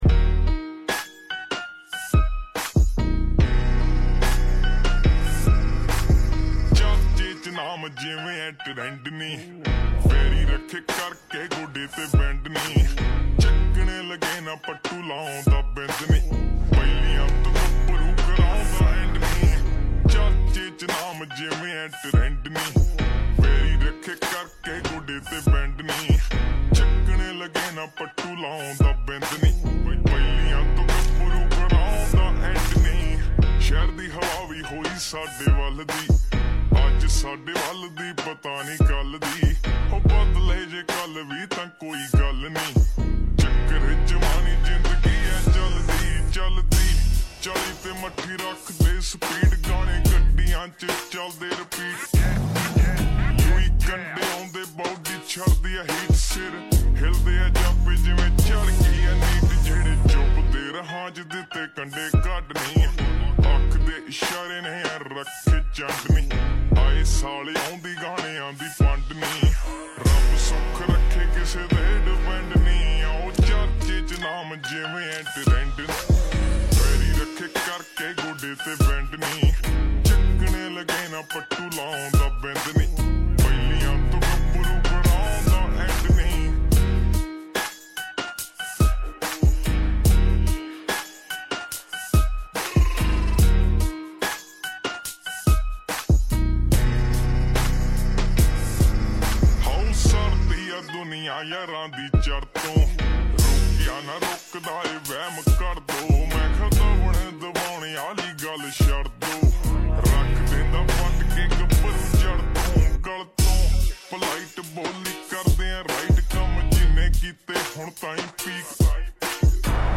Full song slowed reverb